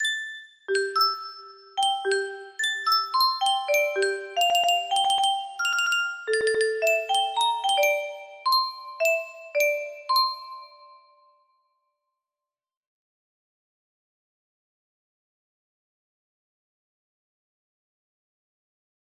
EWEWEWE music box melody